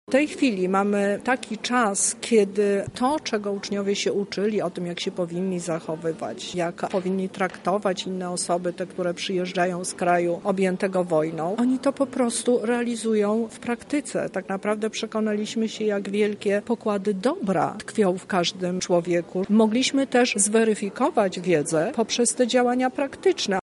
A o to, czy młodzież z polskich placówek odpowiednio ugościła swoich kolegów zza wschodniej granicy zapytaliśmy Teresę Misiuk Lubelską Kurator Oświaty: